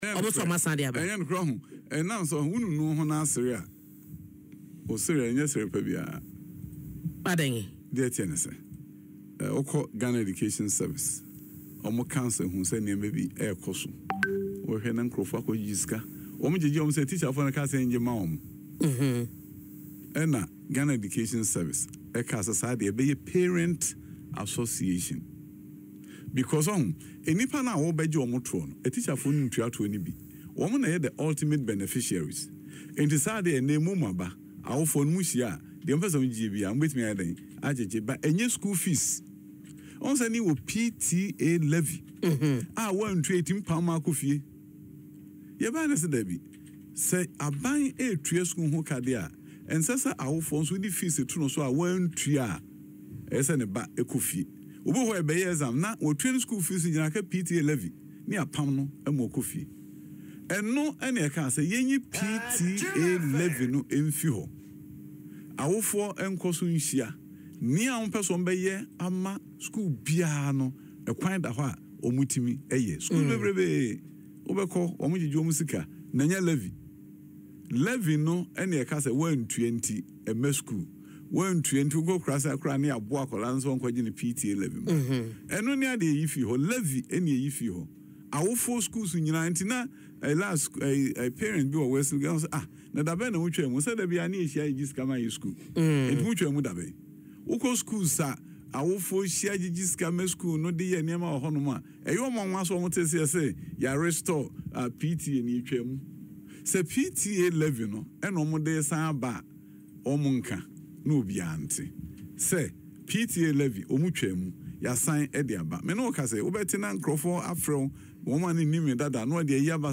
Speaking on Adom FM’s Dwaso Nsem, Dr. Adutwum clarified that PTAs have remained functional, with many schools continuing to collaborate with parents on matters of student welfare, discipline, and school development.